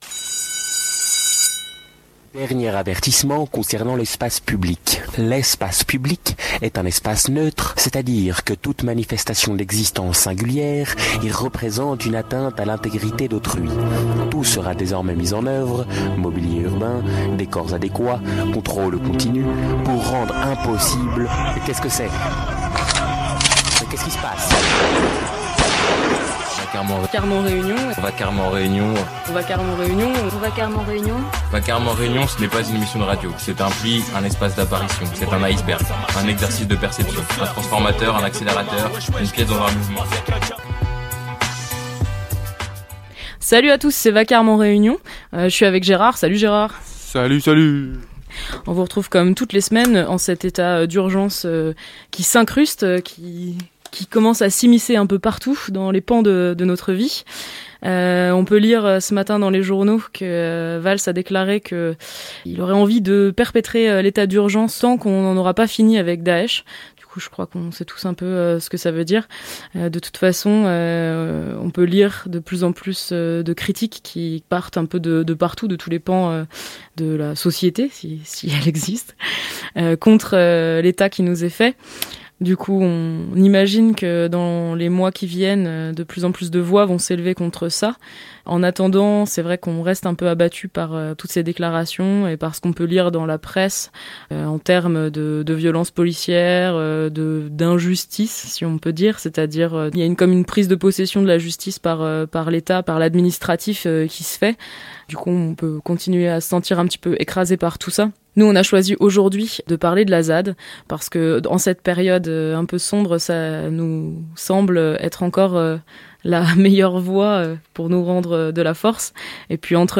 -> Interview de personnes qui reviennent de Calais après avoir assisté à l’expulsion de la dite « Jungle »…